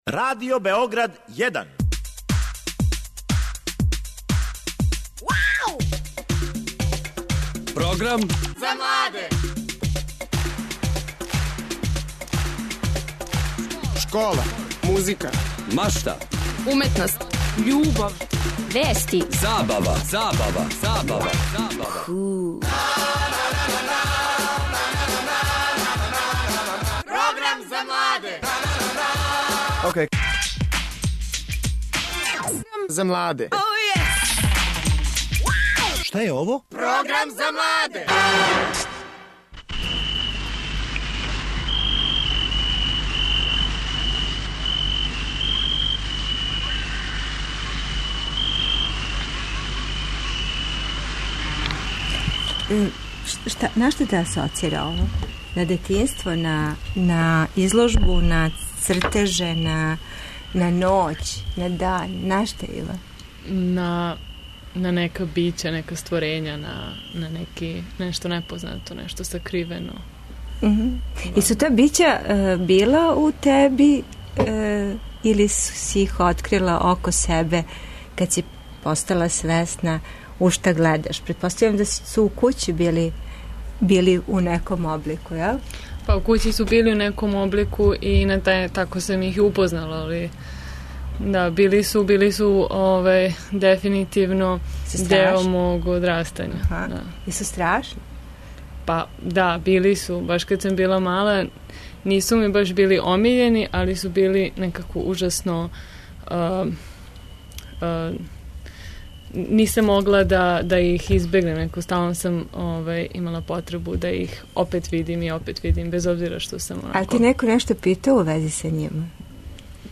A, to, što će danas u Tajnom prolazu biti parni broj voditelja (2) jeste pokušaj da se ovo staro verovanje malo izmeni. Biće to zanimljiv razgovor o (srpskoj) mitologiji, a sve zbog ala i bauka.